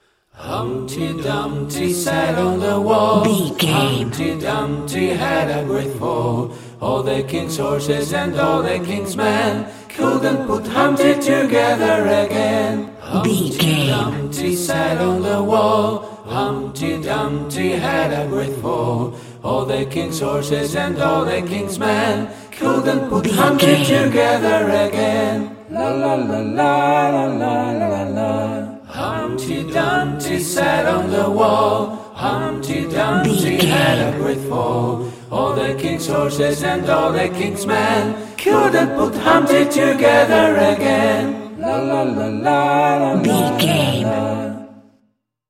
Ionian/Major
nursery rhymes
fun
cute
happy